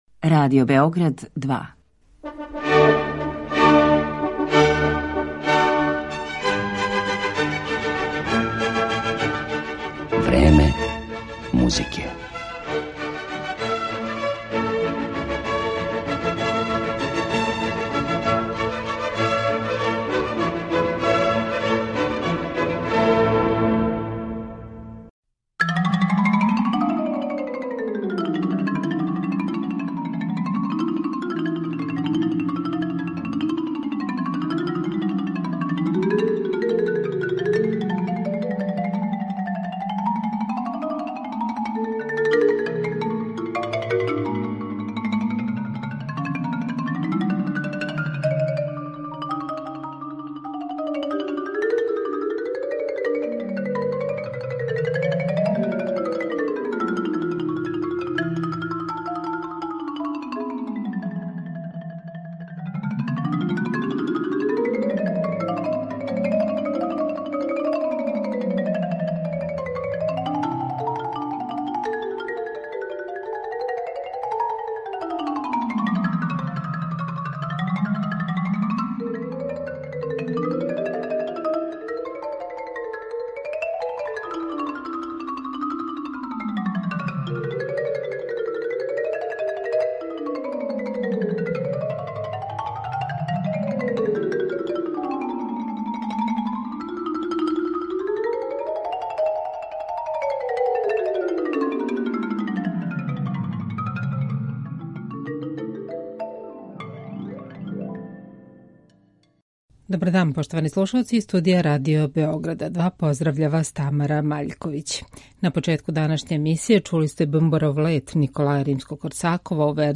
Музика за удараљке
Емисију Време музике посветићемо музици за удараљке.